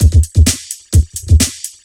ELECTRO 16-L.wav